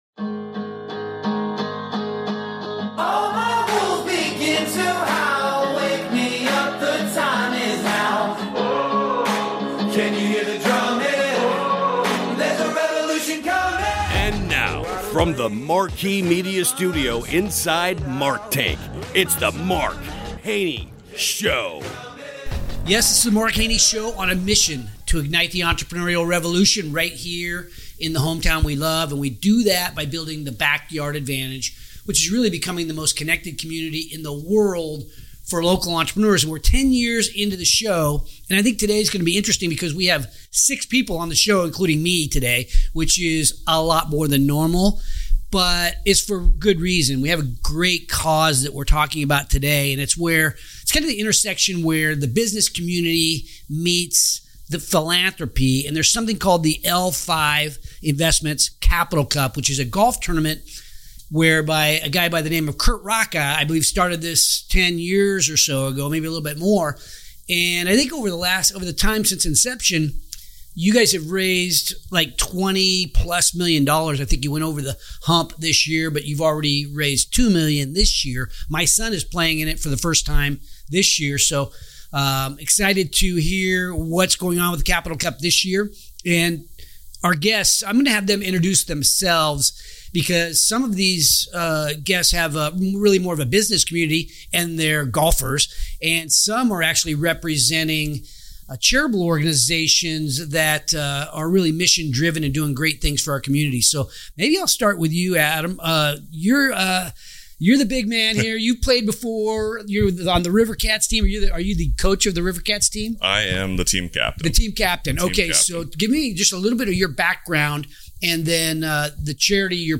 In this special roundtable